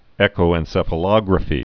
(ĕkō-ĕn-sĕfə-lŏgrə-fē)